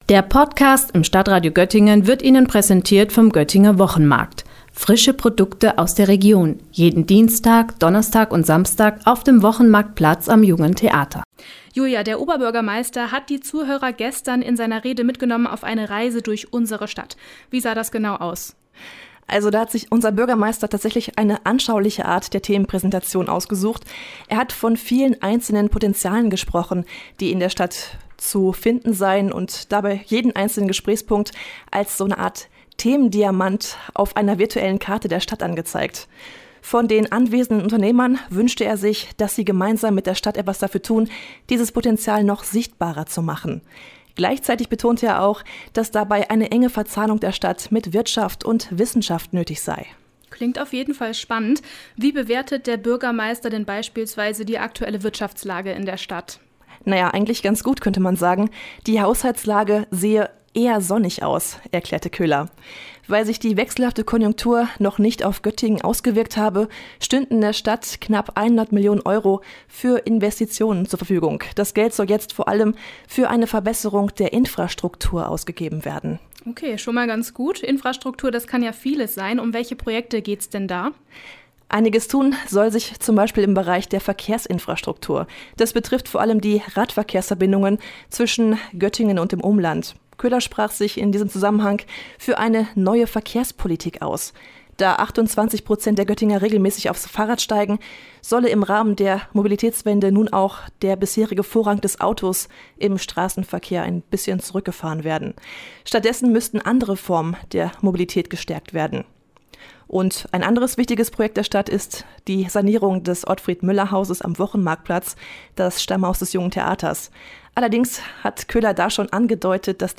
Anlass war der elfte Wirtschaftsempfang der Stadt Göttingen.